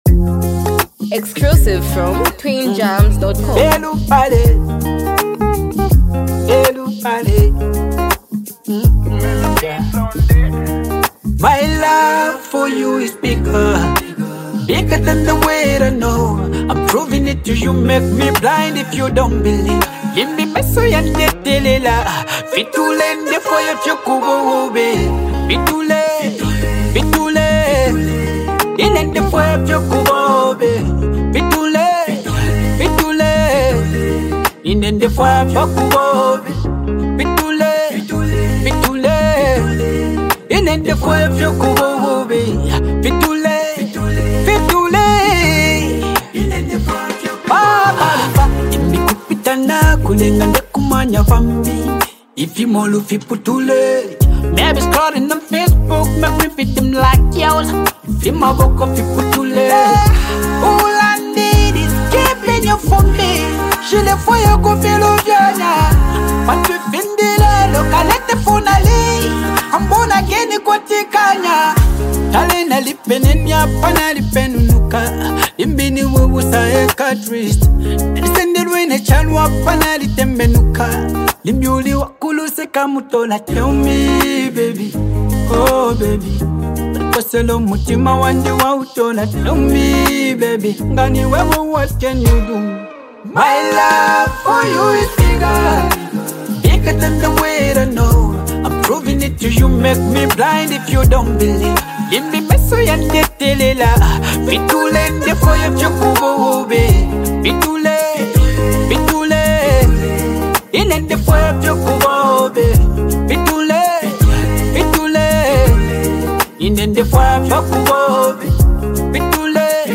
Highly multi talented act and super creative singer